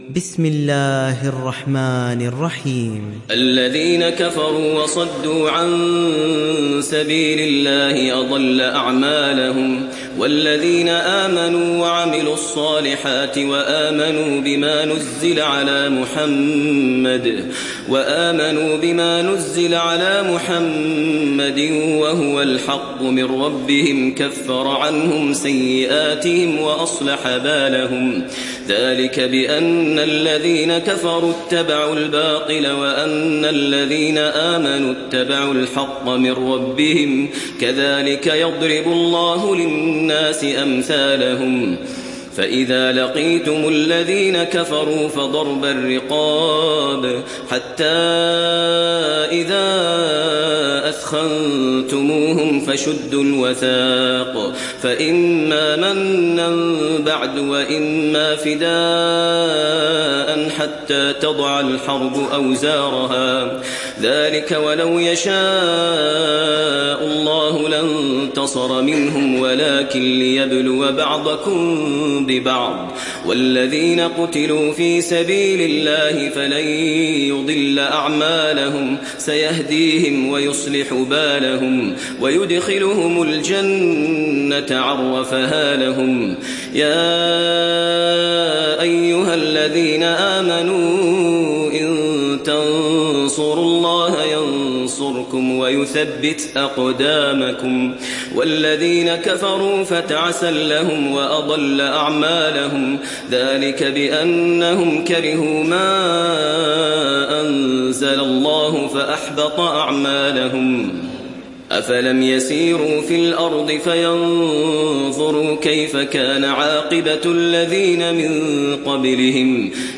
Surat Muhammad Download mp3 Maher Al Muaiqly Riwayat Hafs dari Asim, Download Quran dan mendengarkan mp3 tautan langsung penuh